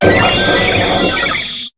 Cri d'Arceus dans Pokémon HOME.